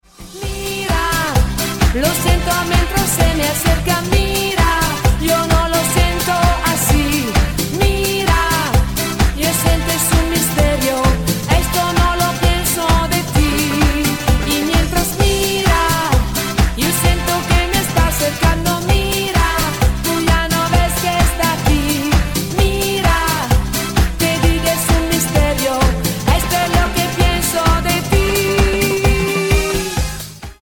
CHA CHA CHA  (3.52)